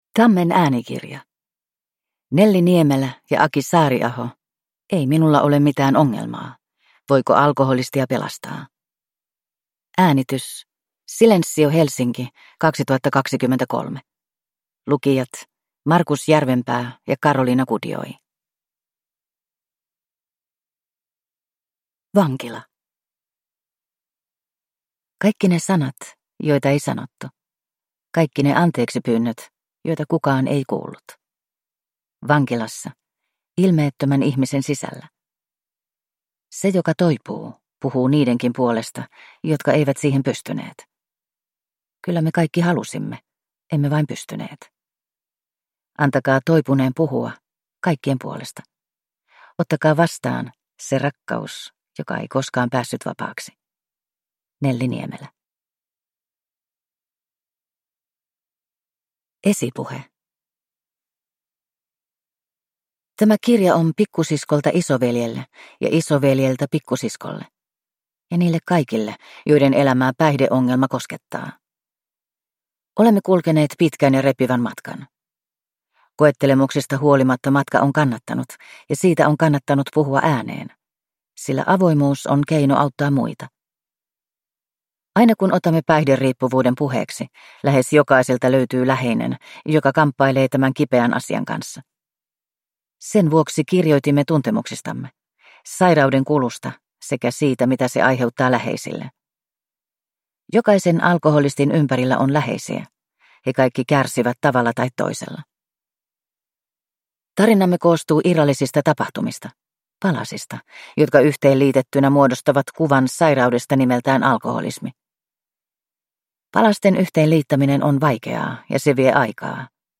Ei minulla ole mitään ongelmaa – Ljudbok – Laddas ner